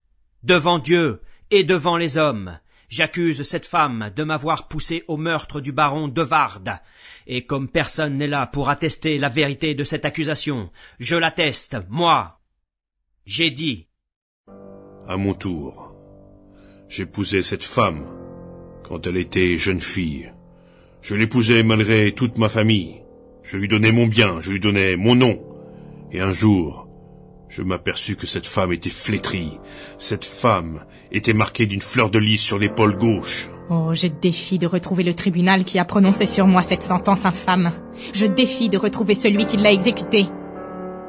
0% Extrait gratuit Les Trois Mousquetaires de Alexandre Dumas Père Éditeur : Compagnie du Savoir Paru le : 2009 Ce spectacle de théâtre sonore est adapté du roman d’Alexandre Dumas. Il raconte les aventures d'un gascon désargenté de 18 ans, d'Artagnan, monté à Paris pour devenir mousquetaire du Roi.